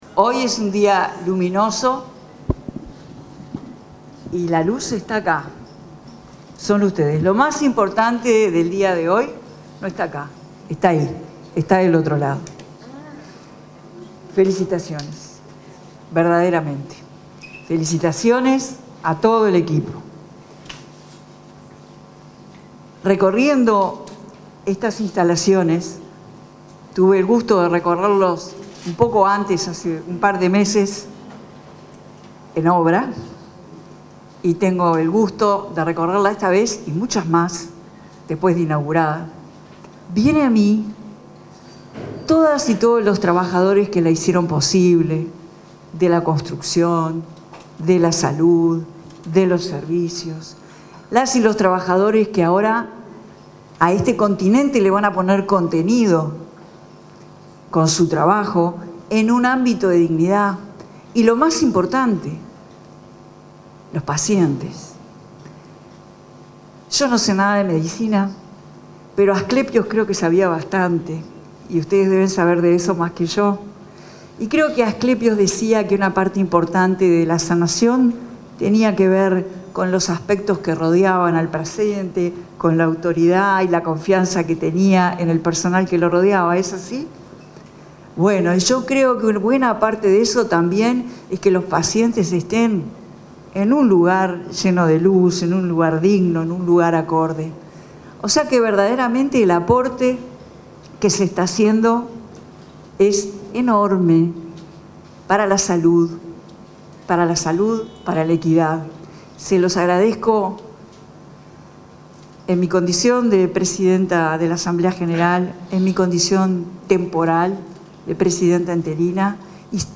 Palabras de autoridades en inauguración de obras en Hospital de Clínicas